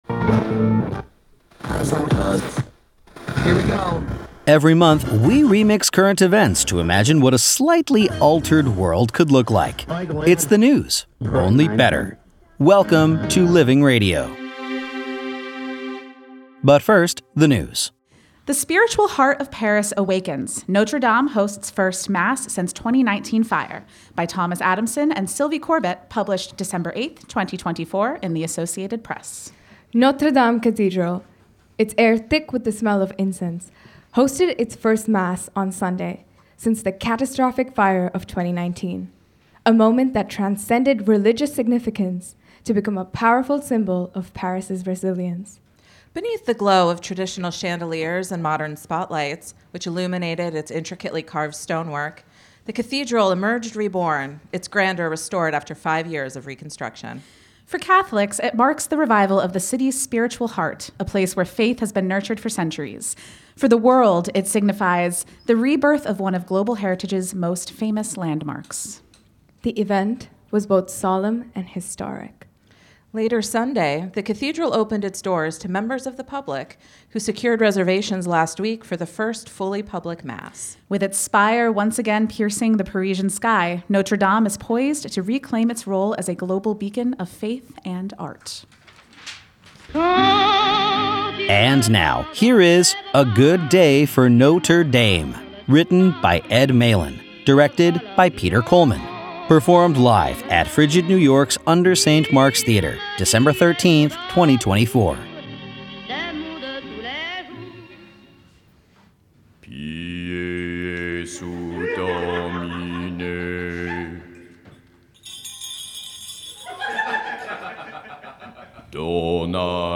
performed live at UNDER St. Mark’s Theater, December 13, 2024